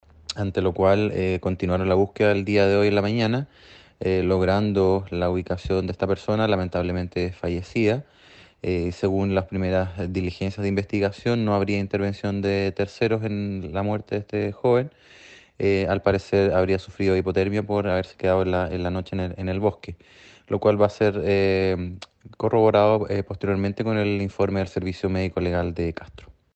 Las tareas de rescate de esta persona se reanudaron este lunes, produciéndose el hallazgo lamentablemente sin vida de este trabajador agrícola, indicó el fiscal Luis Barría.